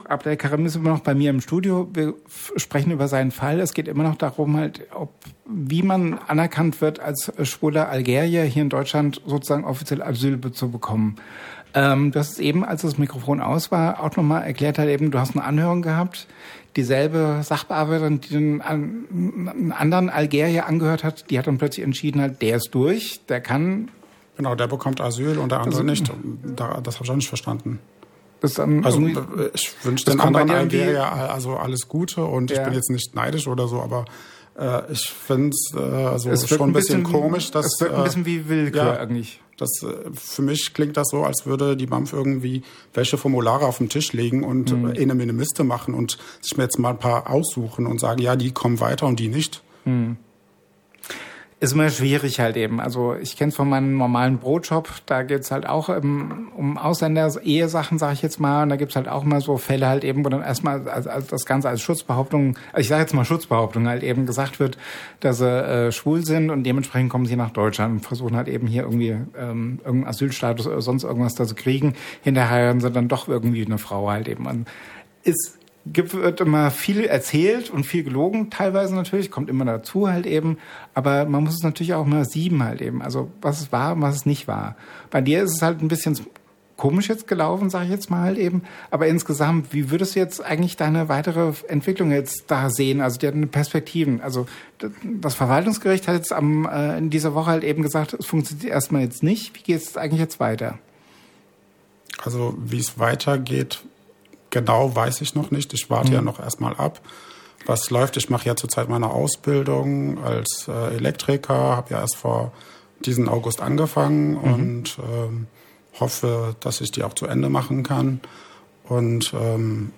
live im Studio